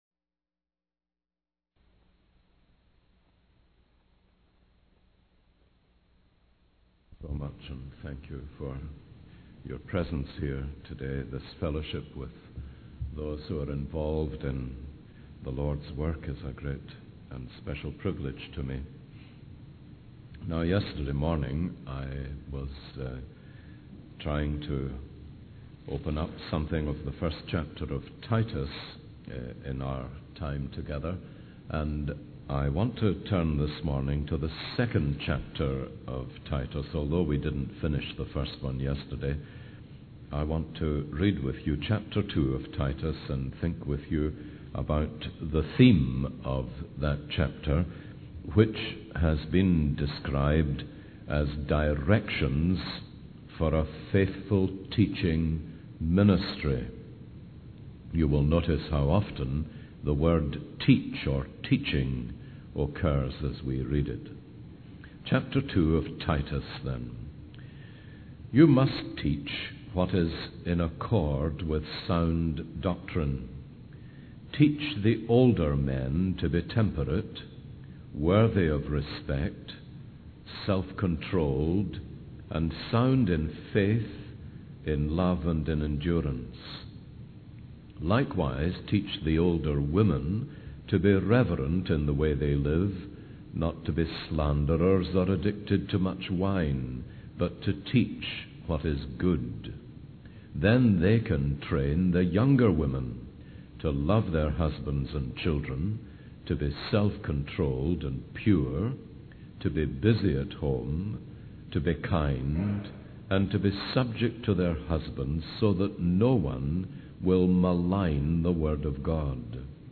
In this sermon, the speaker focuses on the importance of living a godly life and setting a good example for others. He emphasizes the need for integrity, seriousness, and soundness of speech in teaching the word of God. The grace of God is highlighted as the key factor in transforming people's lives and enabling them to live according to God's standards.